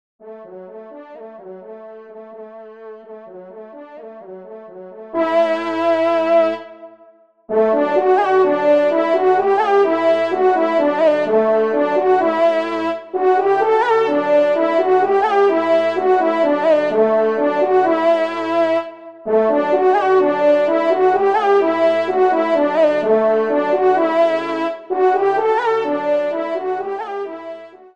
TON DE VÈNERIE   :
Pupitre 1° Cor (en exergue)